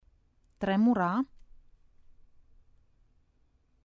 tremura (picioare)